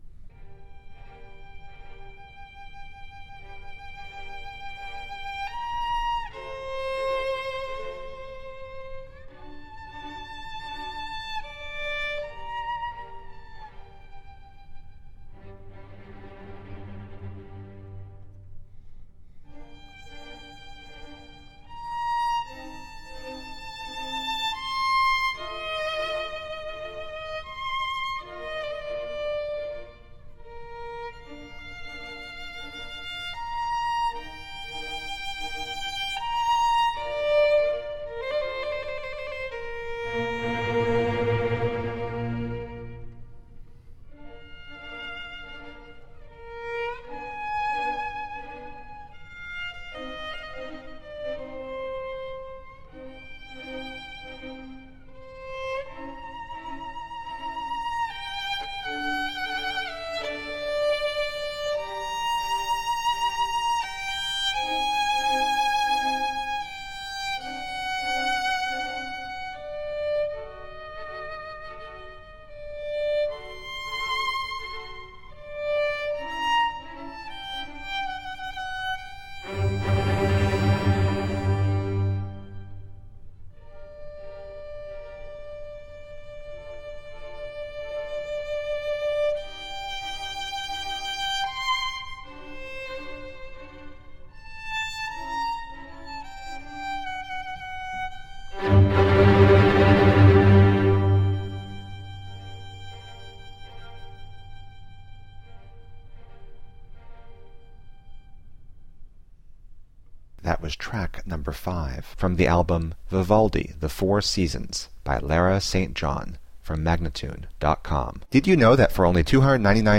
Classical, Baroque, Orchestral, Instrumental, Violin